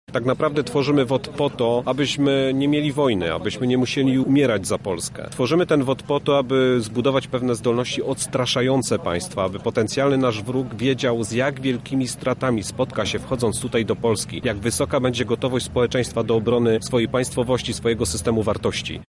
Żołnierze obrony terytorialnej złożyli uroczystą przysięgę.
Jak podkreśla dowódca formacji gen. Wiesław Kukuła – powstała ona w jasno określonym celu: